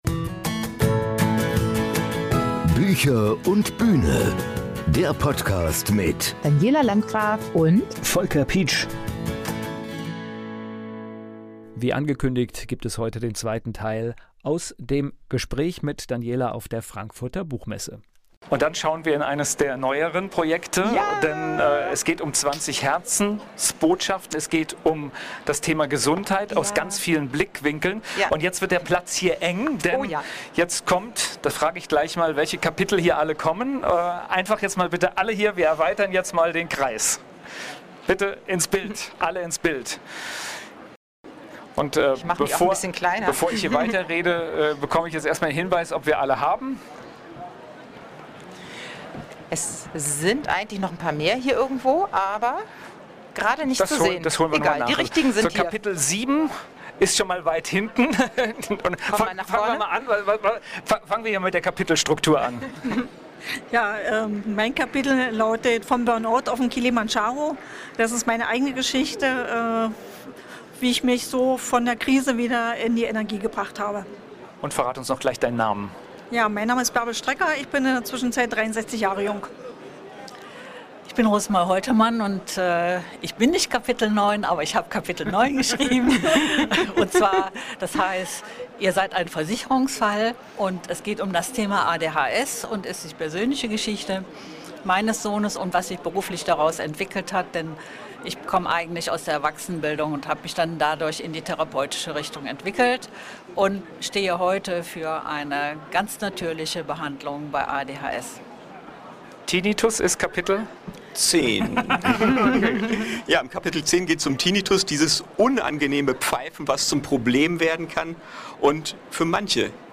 In dieser Episode von Bücher & Bühne erscheint der zweite Teil der Aufzeichnung von der Frankfurter Buchmesse.